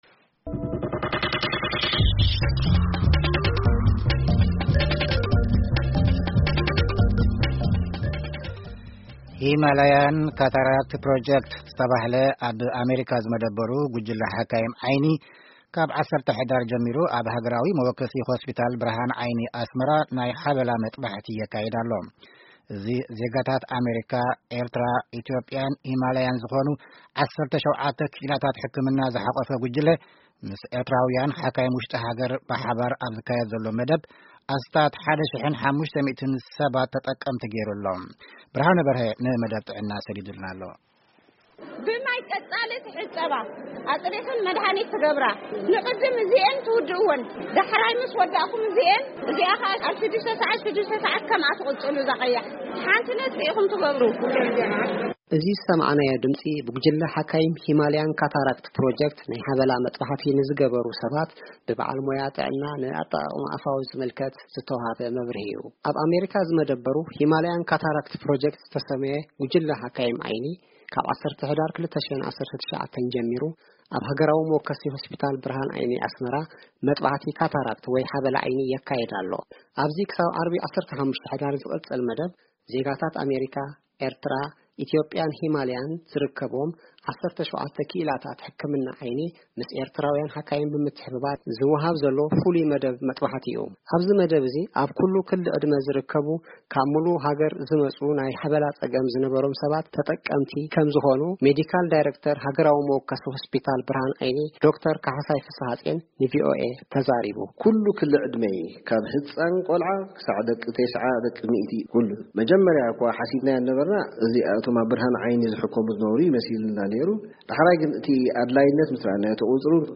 እዚ ብሓባር ዝካያድ መጥባሕቲ ተመክሮ ኣብ ምልውዋጥን ካልእ ዝምድናታትን ኣብ ምፍጣር ዓቢ ተራ ኣለዎ።ክብሉ ኣብቲ መጥባሕቲ ዝሳተፉ ዘለዉ ሓካይም ድምጺ ኣሜሪካ ተዛሪቦም።
ተጠቀምቲ ናይቲ መጥባሕቲ ብግዲኦም፡ሳላ ዝረኸብዎ ናይ ናጻ መጥባሕቲ ክርእዩ ከምዝበቕዑተዛሪቦም።